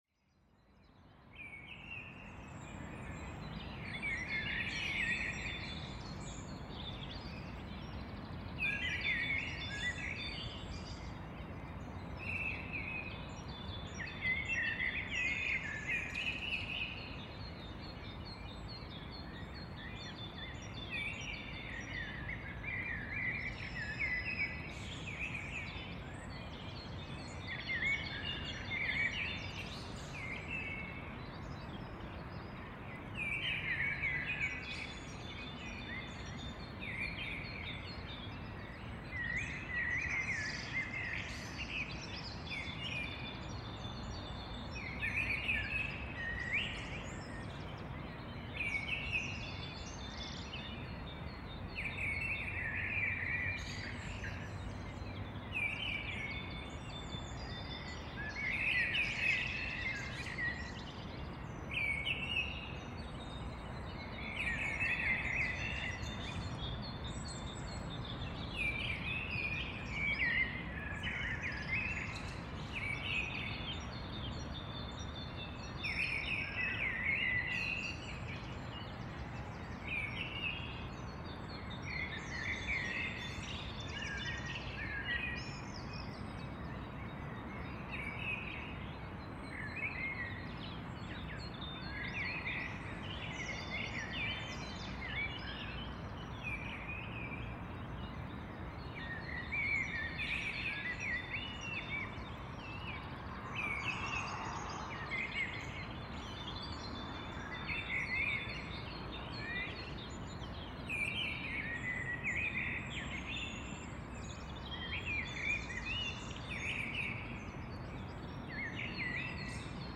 April dawn chorus, Hilly Fields